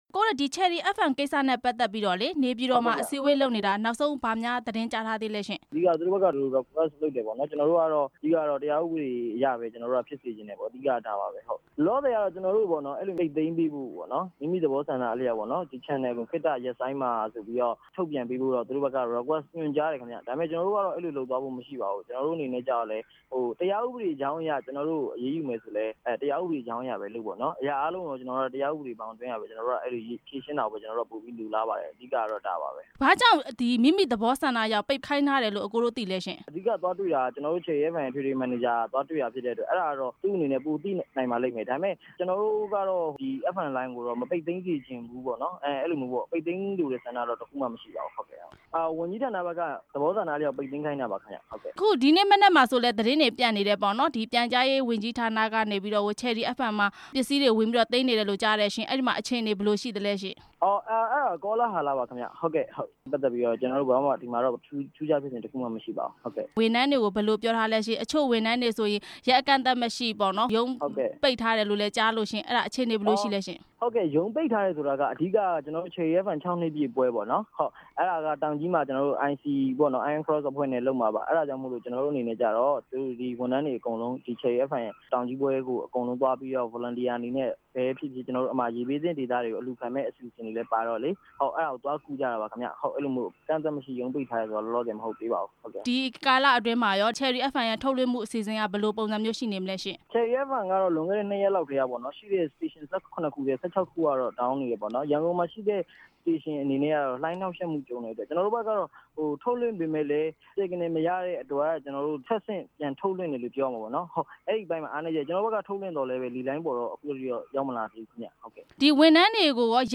ချယ်ရီ FM ရေဒီယိုရဲ့ ရန်ကုန်ရုံး တာဝန်ရှိသူတစ်ဦးနဲ့ မေးမြန်းချက်